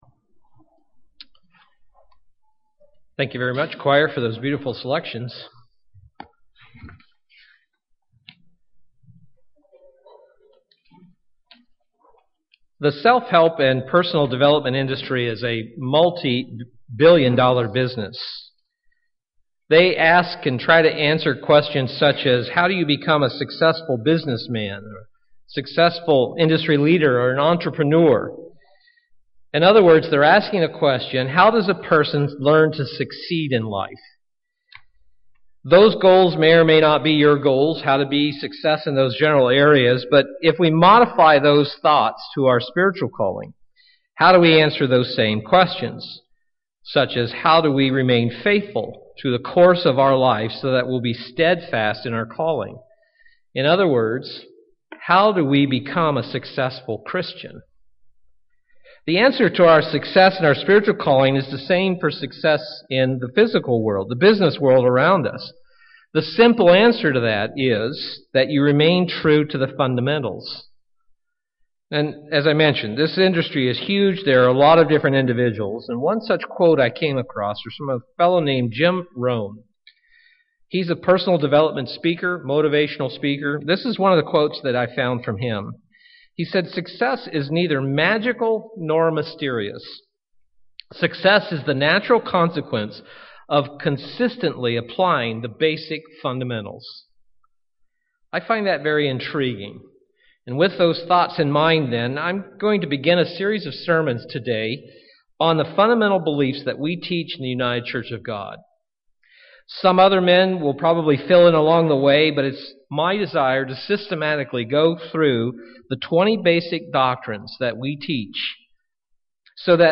Print A review of the first of our Fundamental Beliefs UCG Sermon Studying the bible?